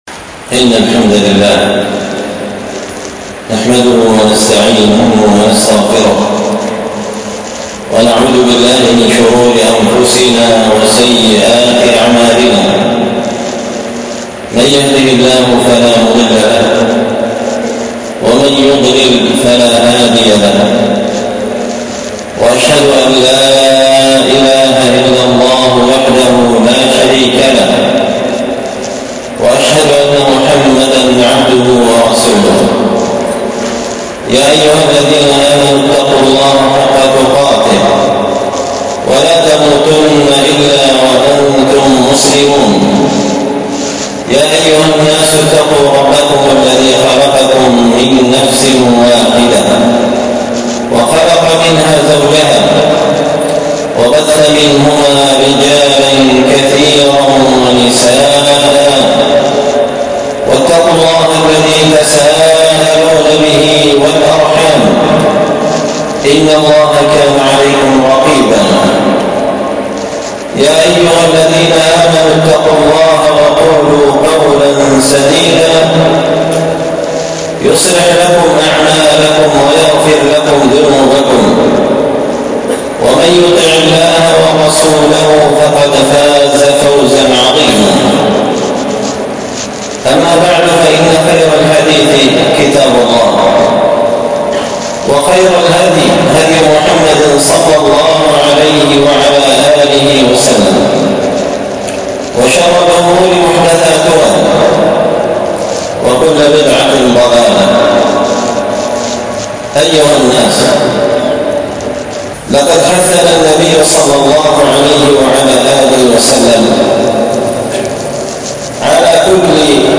ألقيت هذه الخطبة بمسجد الجامع بحرضنوت حصوين-المهرة-اليمن تحميل…
الأحد 6 ربيع الثاني 1447 هــــ | الخطب والمحاضرات والكلمات | شارك بتعليقك | 31 المشاهدات